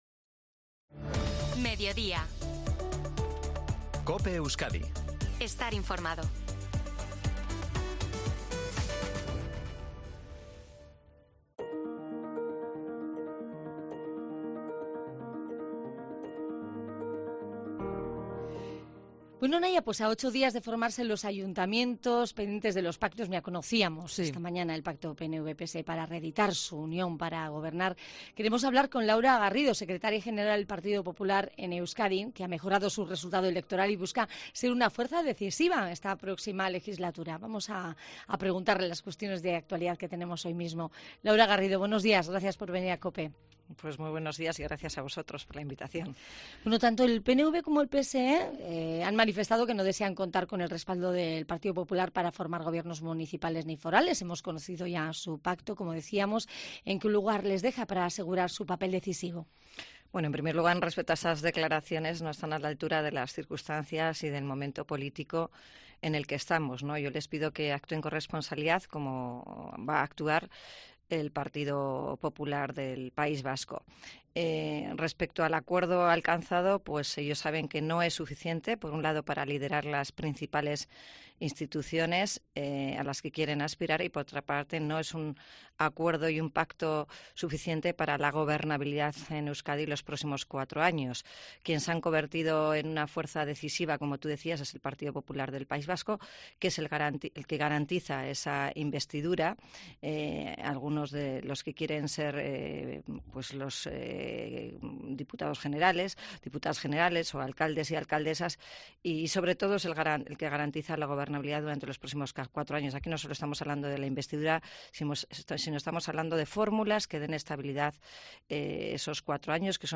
En relación al acuerdo anunciado esta mañana entre PNV y el PSE-EE para la gobernabilidad en los municipios y diputaciones, la secretaria general del PP vasco, Laura Garrido, ha reaccionado al pacto en una entrevista en COPE Euskadi, adviertiendo que lo acordado entre PNV y PSE-EE "no es suficiente" tanto para investir alcaldes y diputados generales para gobernar las instituciones.